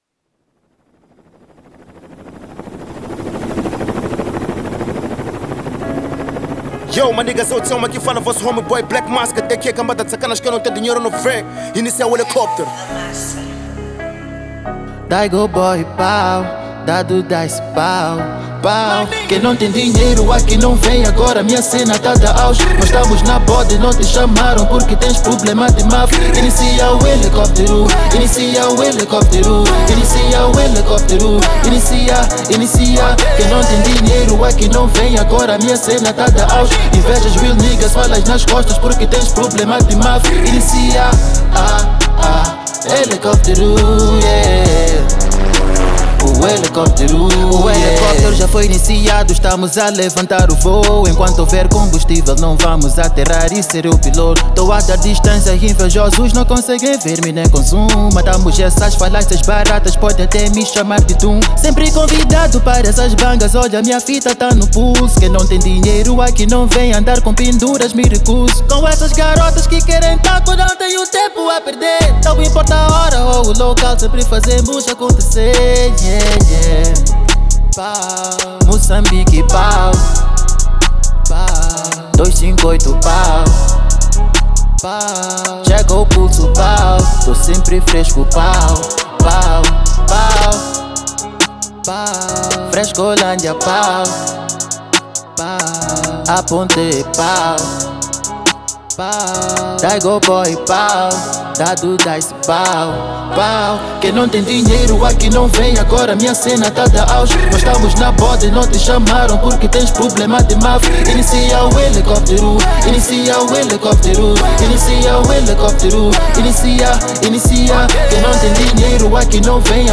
Hip-Hop Rap 2022 Download Mp3